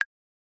tink.mp3